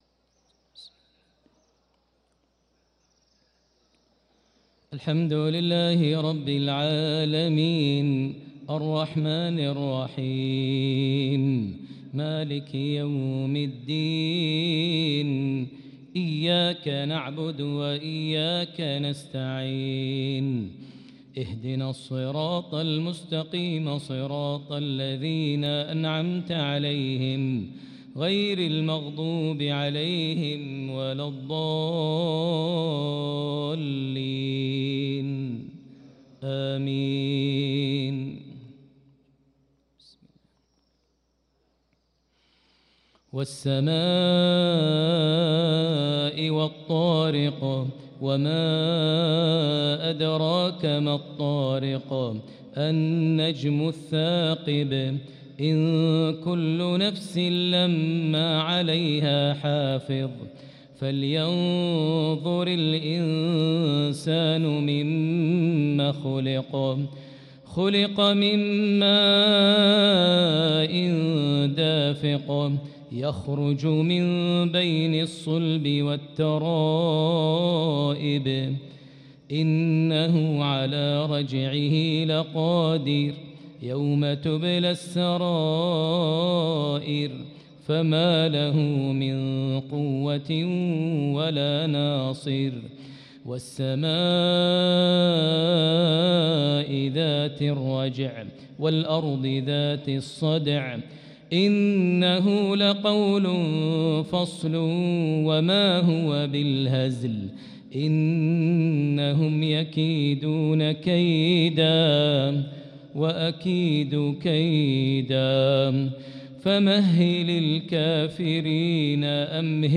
صلاة المغرب للقارئ ماهر المعيقلي 7 شعبان 1445 هـ
تِلَاوَات الْحَرَمَيْن .